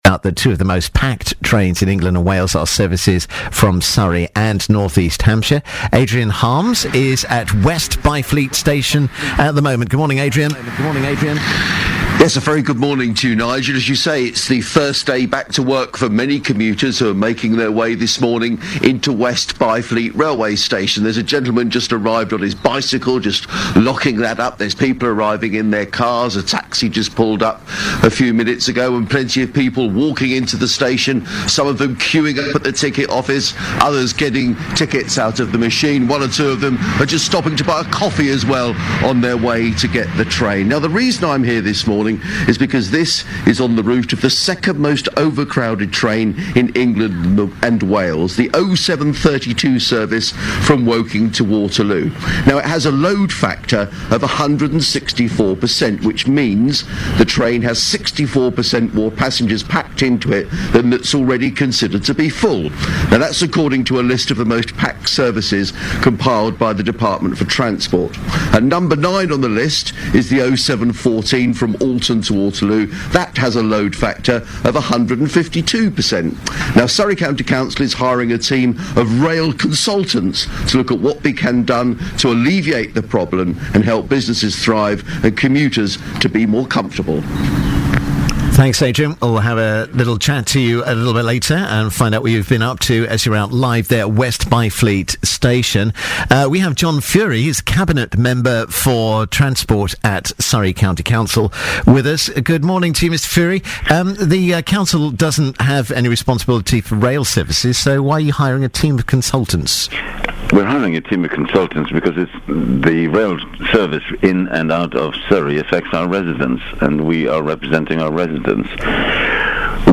BBC radio interview on Surrey’s rail strategy
Cabinet member for transport and environment John Furey was interviewed about it on BBC Surrey’s breakfast show.
rail-strategy-interview.mp3